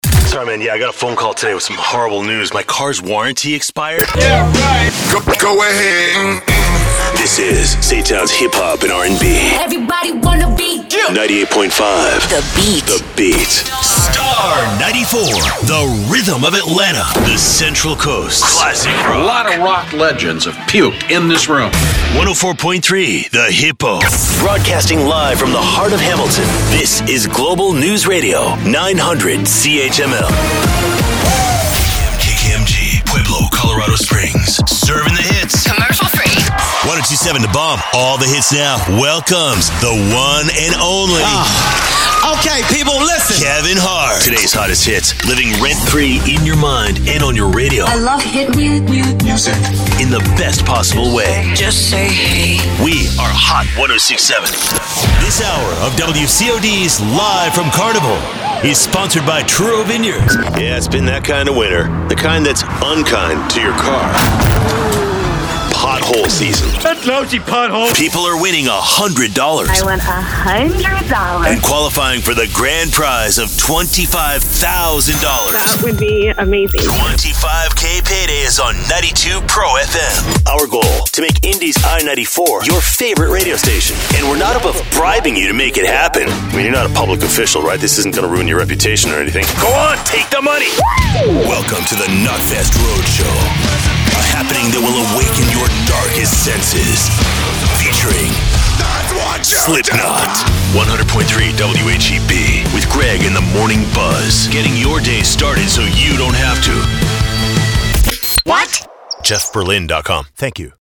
Dynamic Voice Talent ✨ for all media